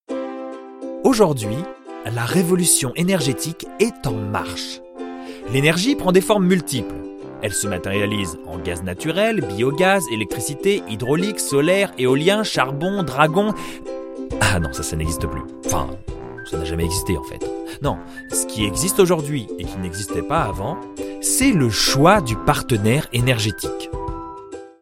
Institutionnel
EDF: voix medium naturelle